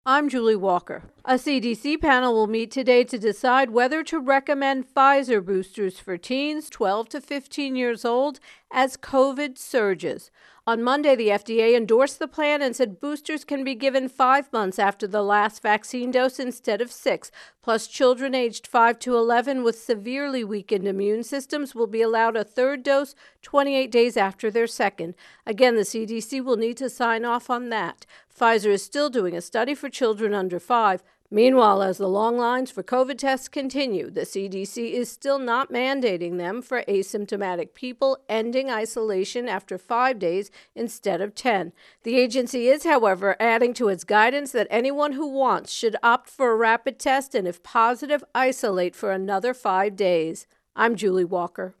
self contained intro + voicer for Virus Outbreak Pfizer Teen Boosters